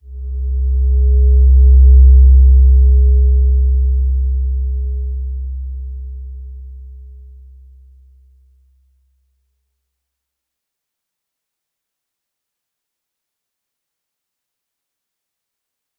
Slow-Distant-Chime-C2-mf.wav